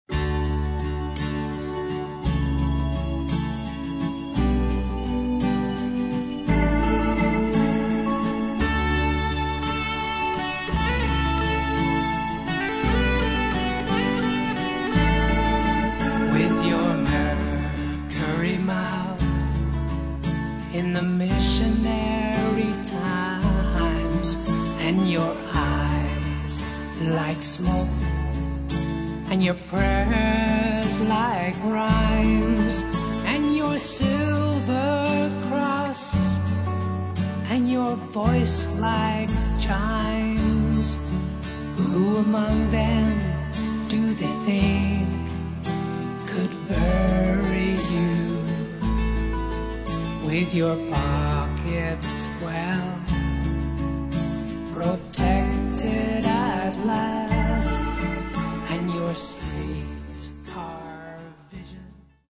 Рок
акустическая гитара, гитара, бас, клавиши, вокал